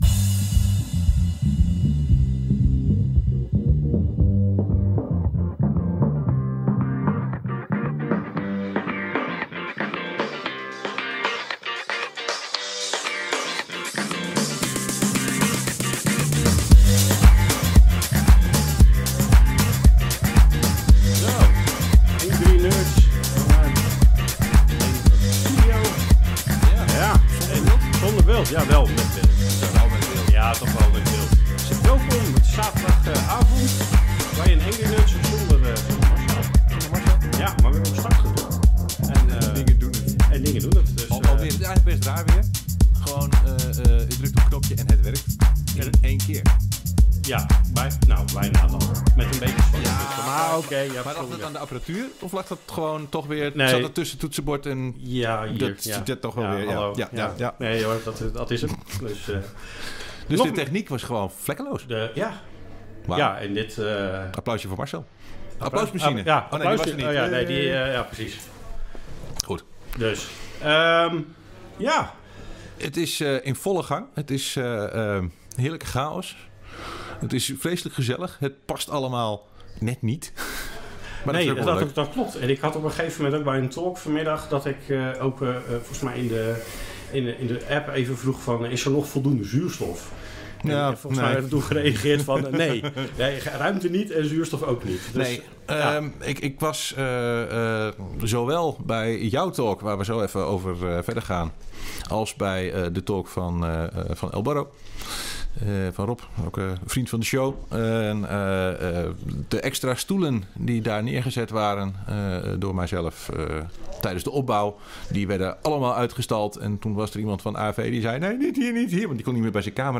Angry Nerds Live op HackerHotel 2025 (zaterdag) - Satellite TV.mp3